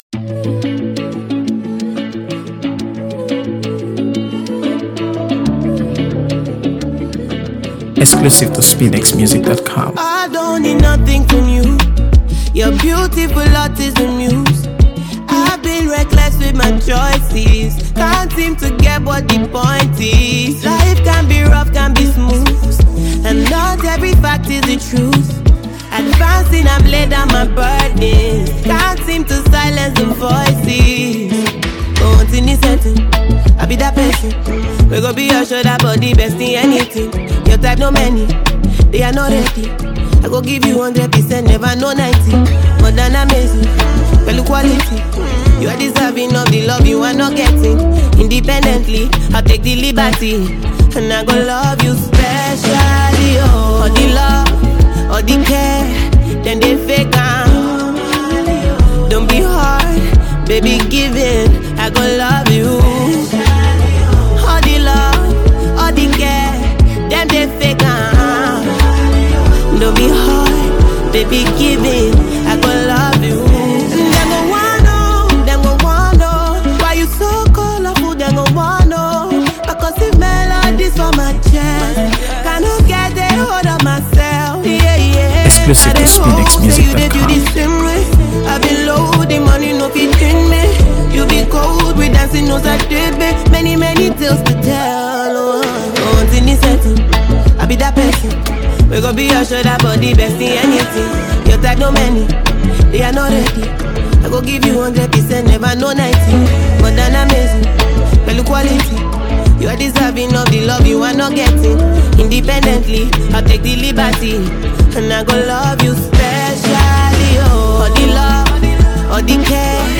AfroBeats | AfroBeats songs
With heartfelt lyrics and soulful vocals